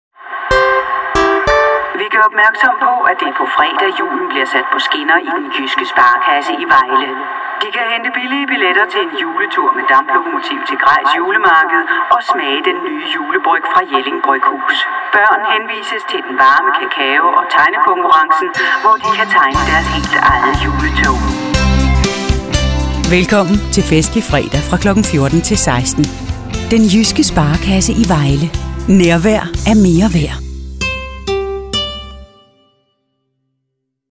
Profi Sprecherin dänisch. Natural speech. Warm, Persuasive, Mature, Narration, commercials, telephone systems
Sprechprobe: Industrie (Muttersprache):
Highly experienced professional female danish voice over artist. Natural speech. Warm, Persuasive, Mature, Narration, commercials, telephone systems